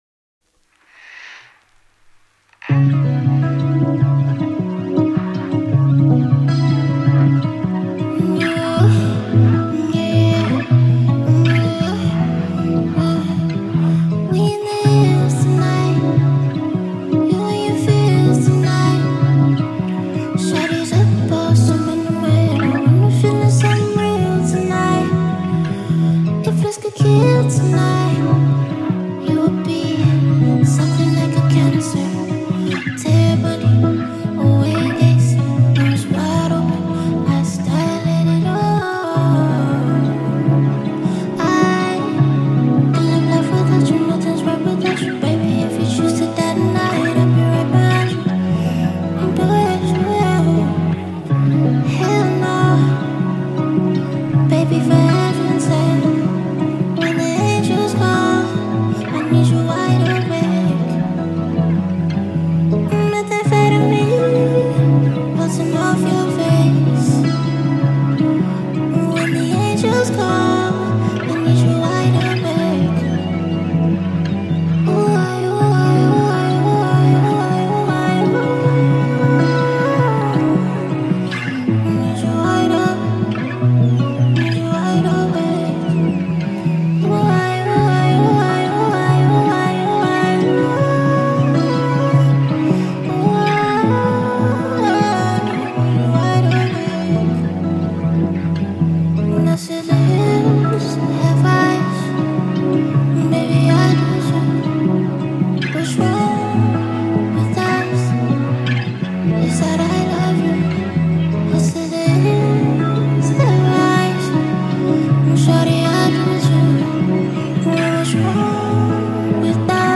Ghanaian female singer and songwriter